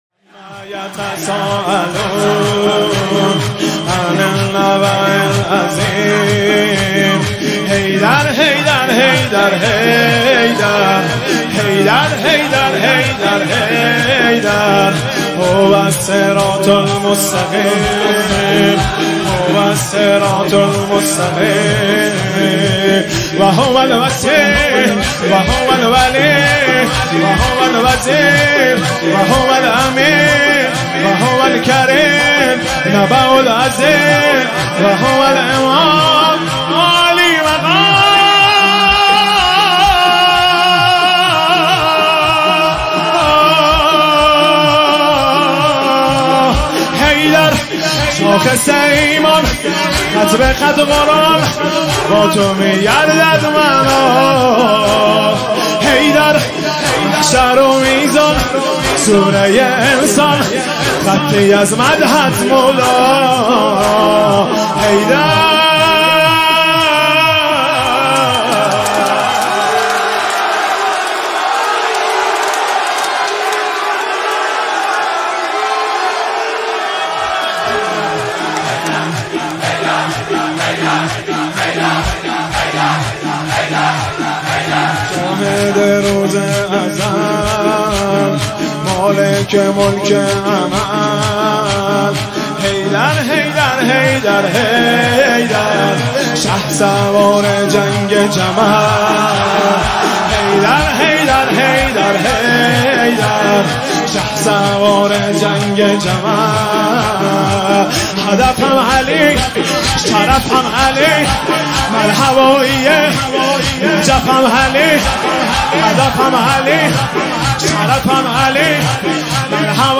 مداحی آذری نوحه ترکی